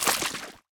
poly_gore02.wav